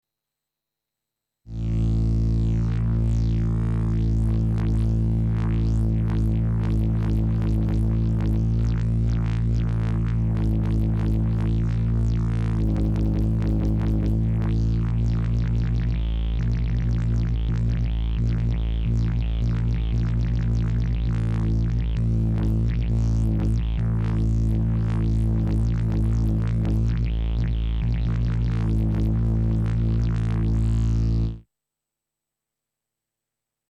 Sauf mention explicite, les enregistrements sont faits sans aucun effet.
p. 78 – MS-20 : un didjeridoo aussi vrai que nature et… qui sonne aussi comme un sabre laser !
MS-20 didjeridoo laser
MS-20-didjeridoo-laser.mp3